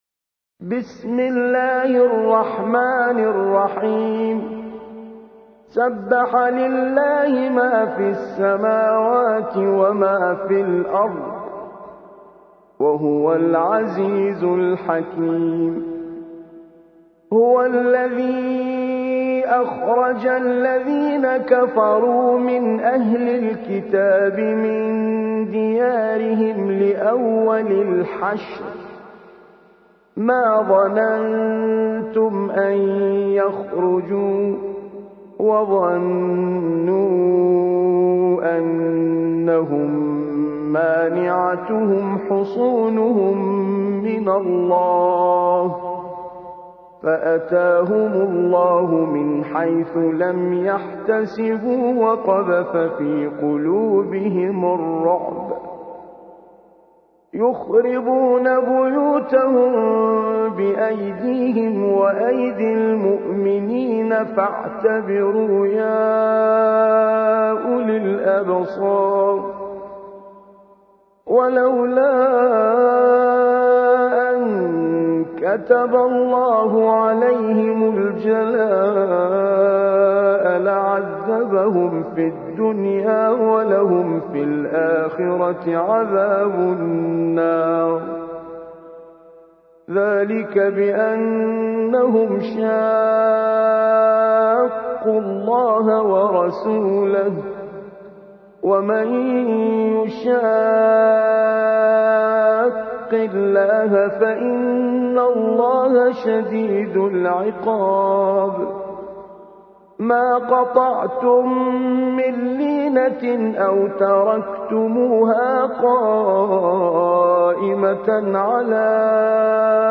59. سورة الحشر / القارئ